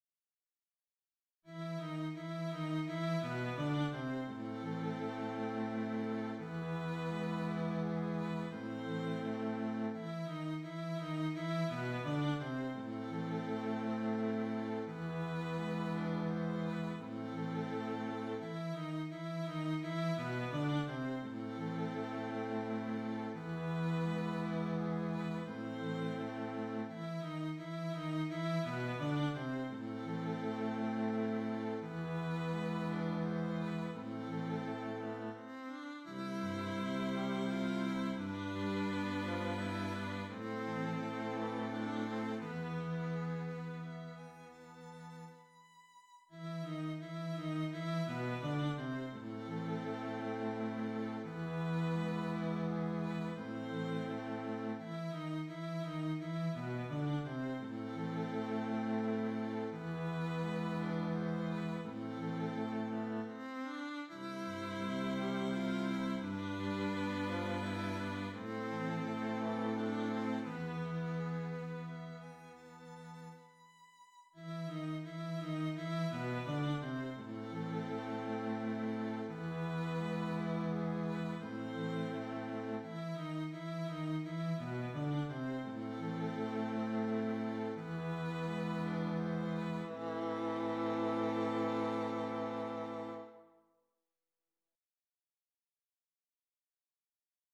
Classical.